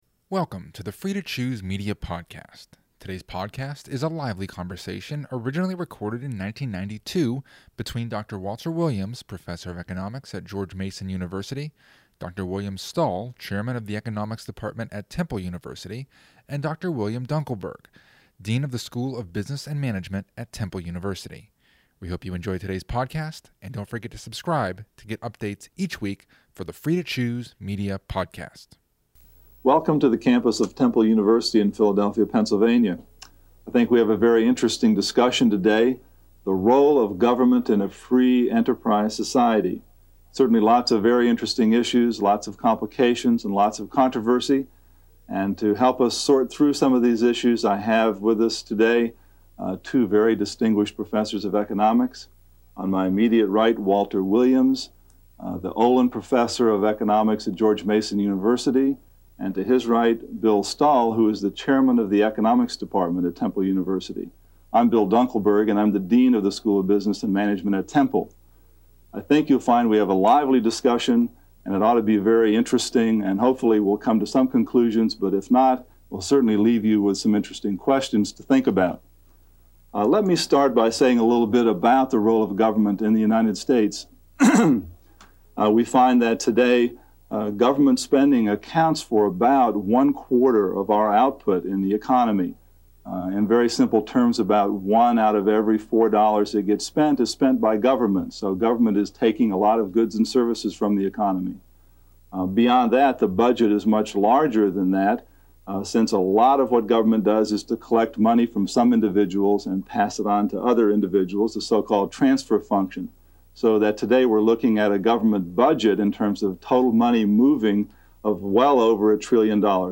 a 45-minute lively discussion